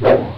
cpu_attack.ogg